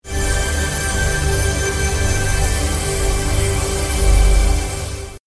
Chest Fortnite Sound Effect Free Download